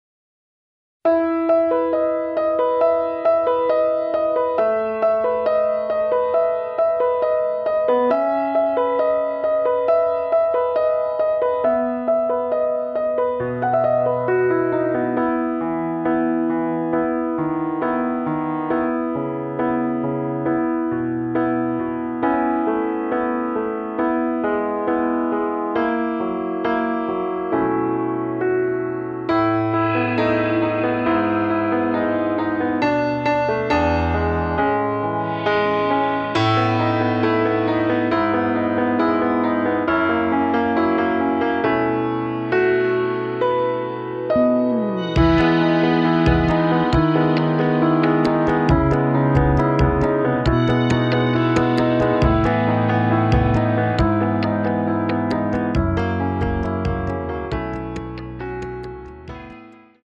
[공식 음원 MR]
앞부분30초, 뒷부분30초씩 편집해서 올려 드리고 있습니다.
중간에 음이 끈어지고 다시 나오는 이유는
위처럼 미리듣기를 만들어서 그렇습니다.